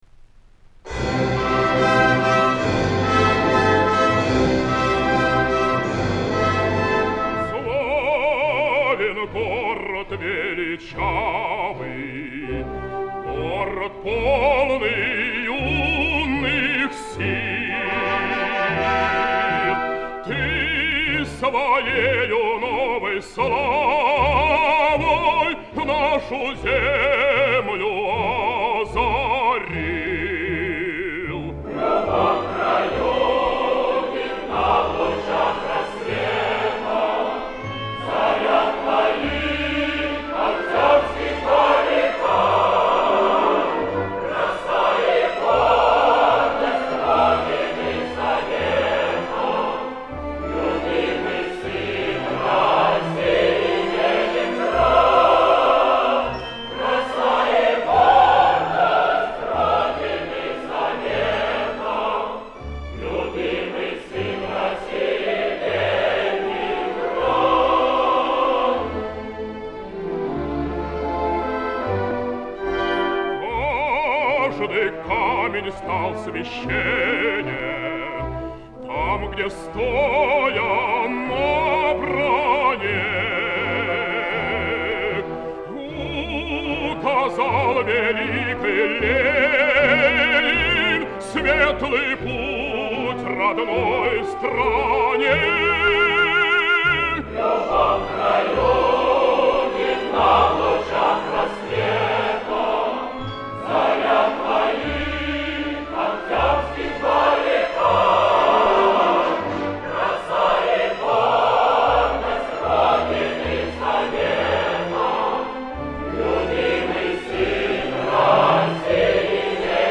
Запись конца 1950-х - начала 1960-х гг.